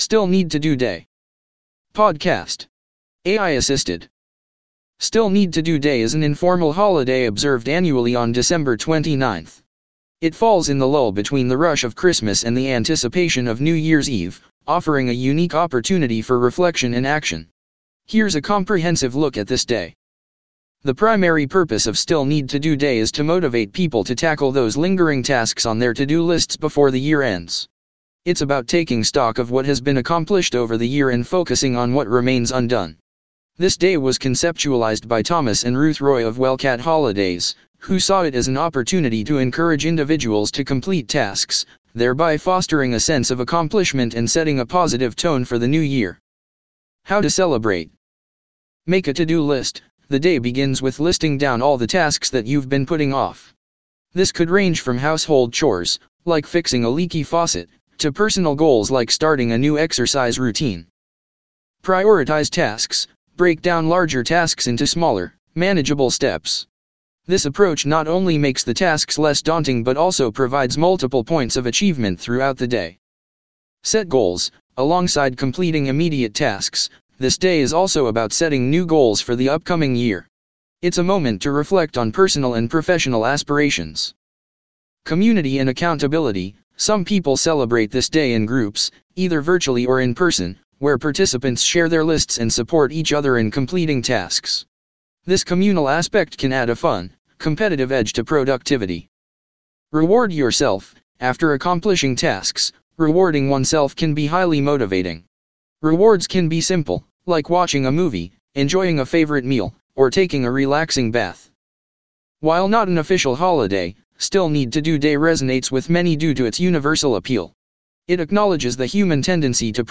PODCAST. AI assisted.